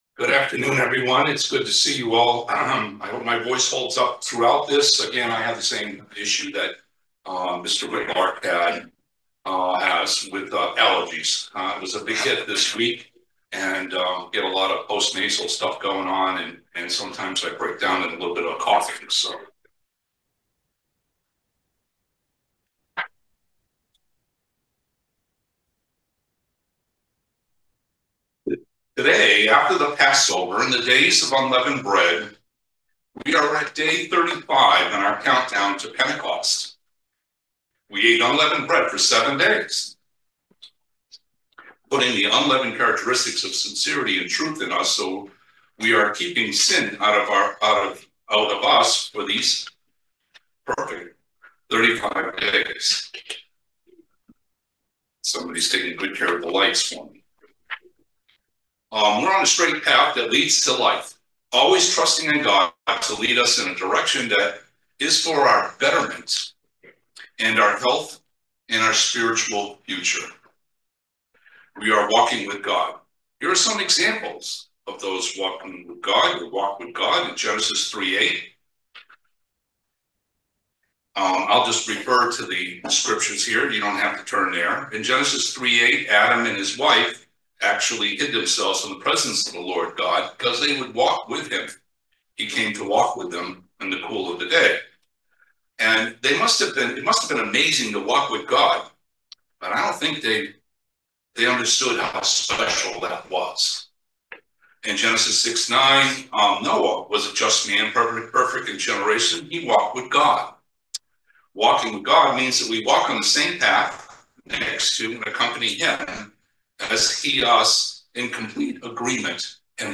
Join us for this very interesting video sermon on the subject of walking with God.